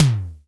TOM SIMM T2.wav